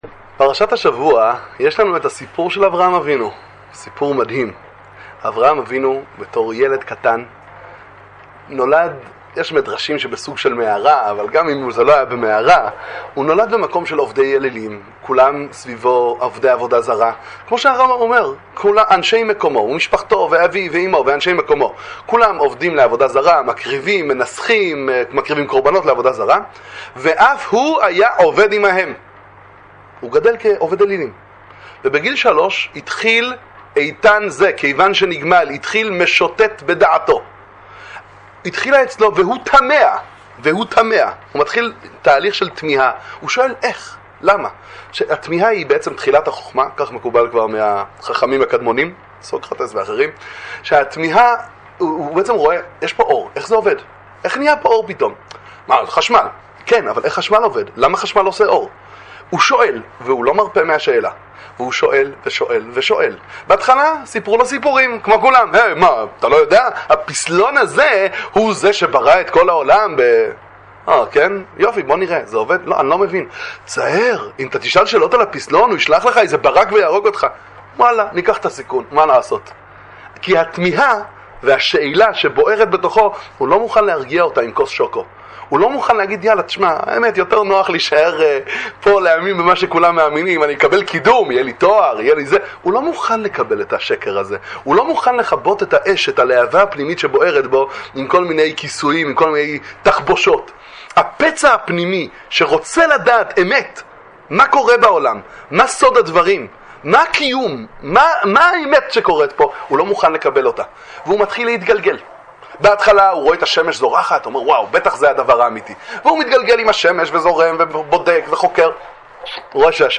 דבר תורה קצר לפרשת לך לך, שיעורי תורה לצפיה על פרשת השבוע, אמונה מול כפירה